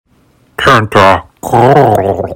Audio / SE / Cries / TENTACRUEL.mp3
TENTACRUEL.mp3